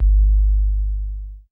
JJCustom808s (4).WAV